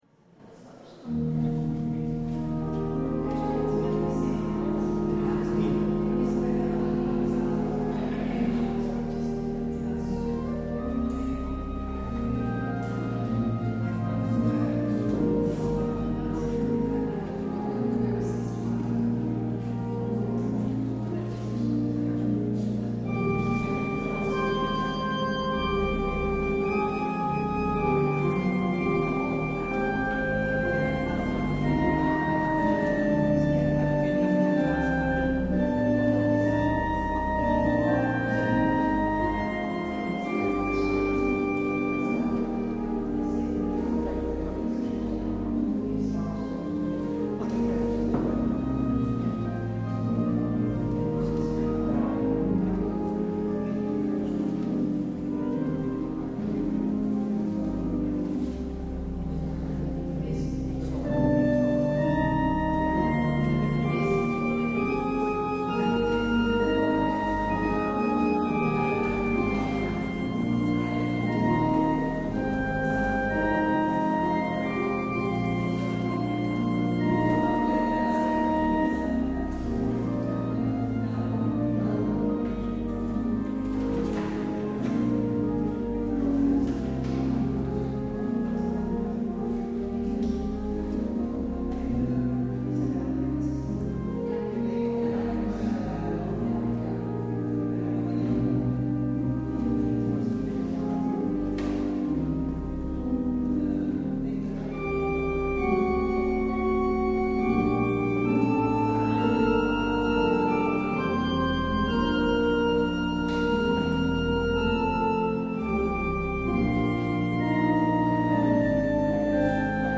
 Luister deze kerkdienst hier terug
Het openingslied is LB 272 – wij zoeken in uw huis of Psalm 123 : 1 NPB. Als slotlied LB 997 – en vele duizenden, ontheemd.